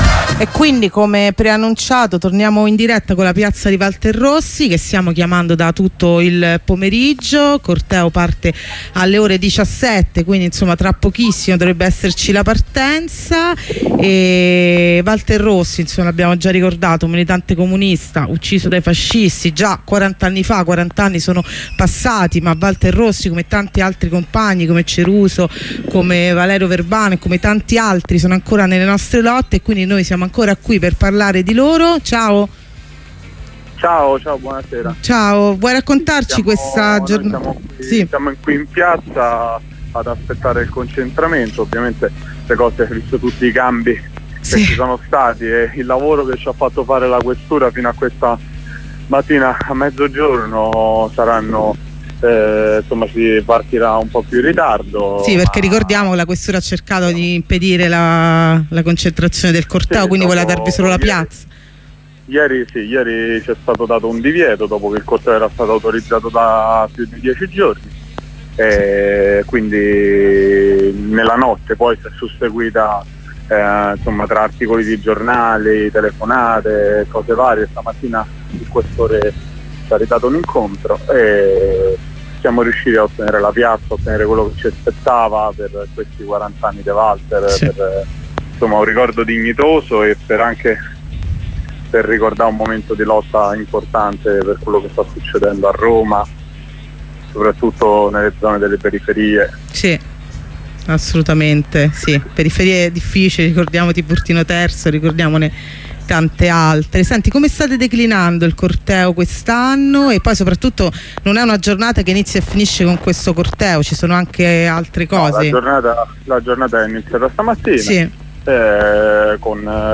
Una corrispondenza dal concentramento della manifestazione per il quarantennale dell'omicidio di Walter Rossi. Dopo il teatrino della questura che voleva vietare il corteo si parte da via Trionfale.